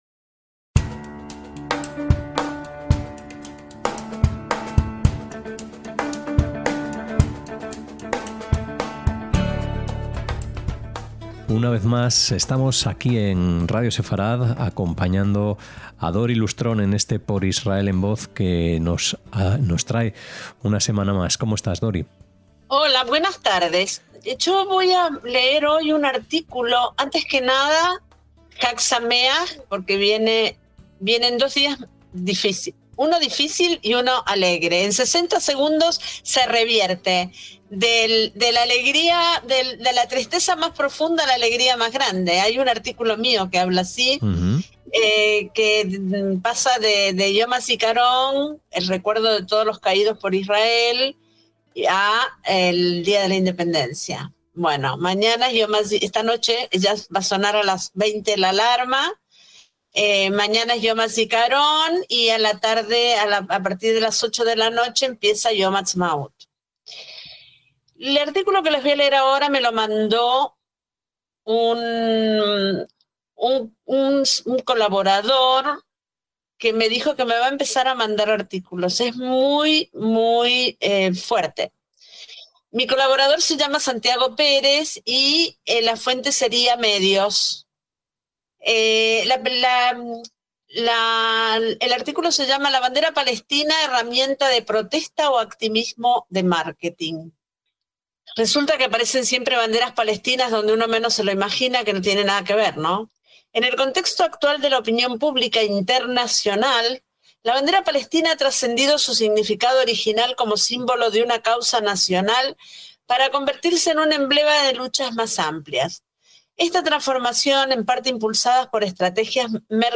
comparte su narración de un artículo firmado por uno de sus nuevos colaboradores